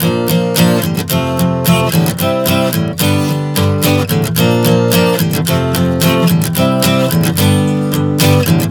Prog 110 Bb-F-C-G.wav